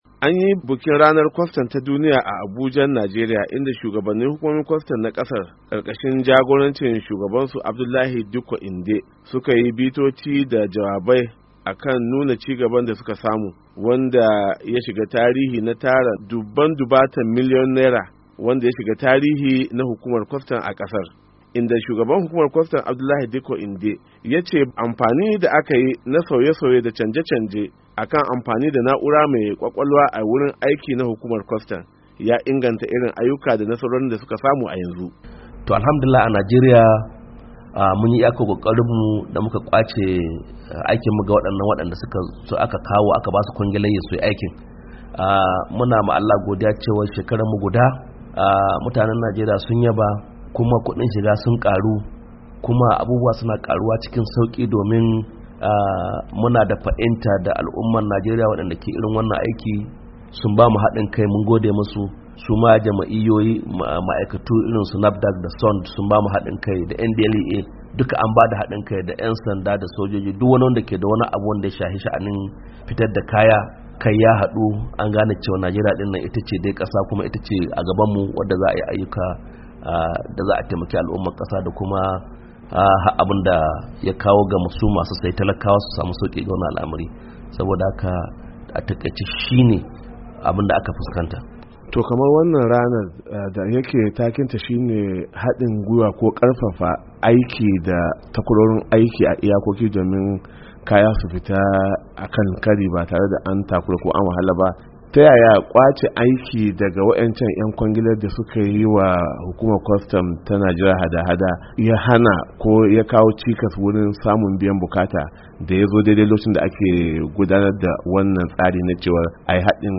An gudanar da bikin ranar kwastan ta duniya a Abujan Najeriya.